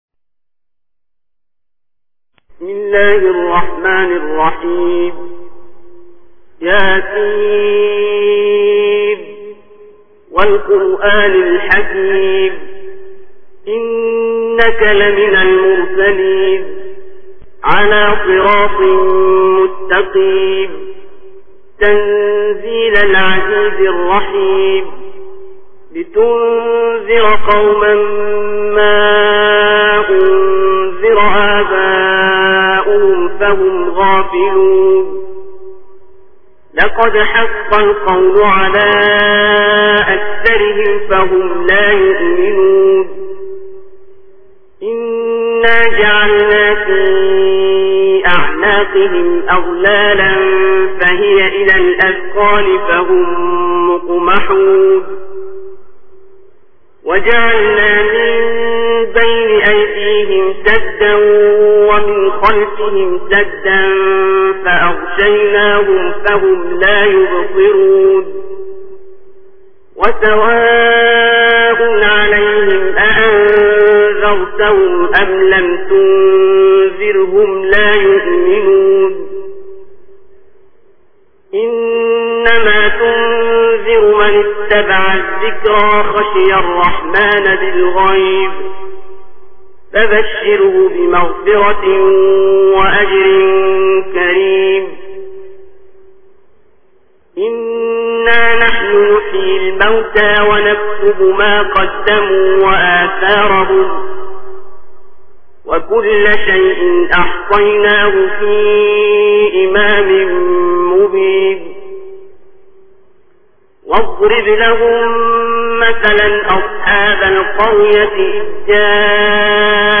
متن و ترجمه سوره یس با نوای استاد عبدالباسط